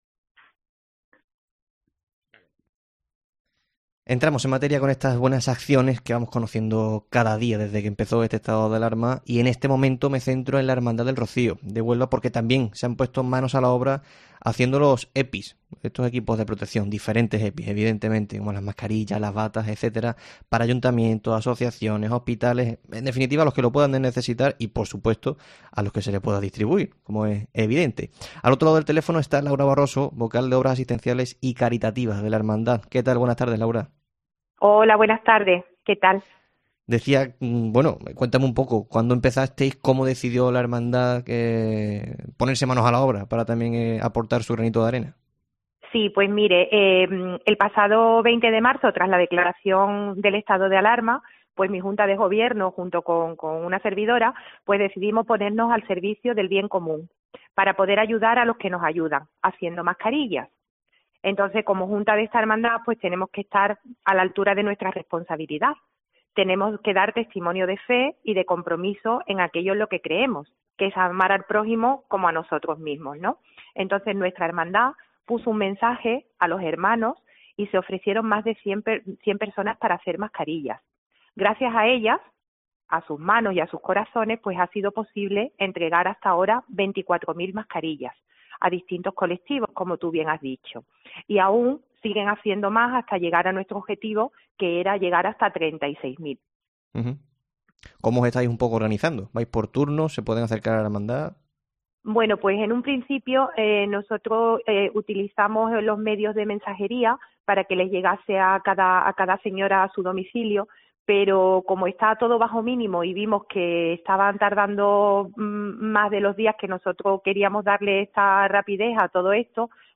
En el tiempo local de Herrera en COPE hablamos